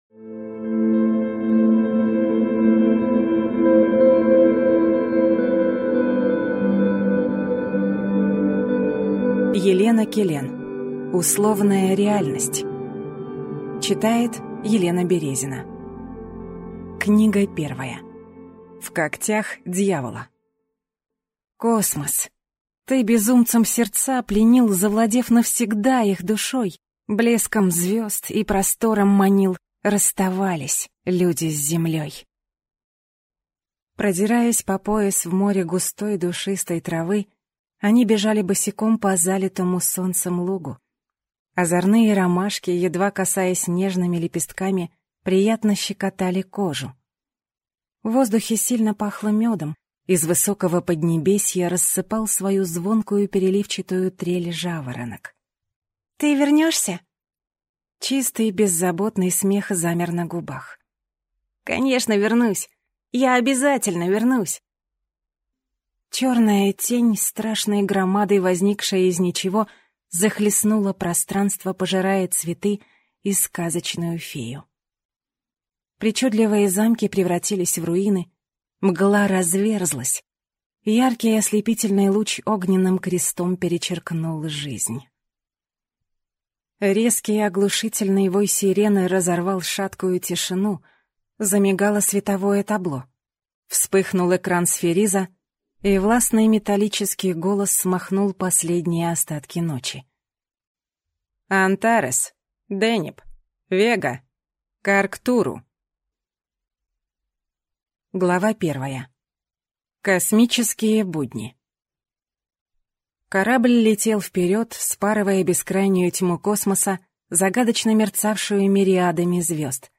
Аудиокнига Условная реальность | Библиотека аудиокниг
Прослушать и бесплатно скачать фрагмент аудиокниги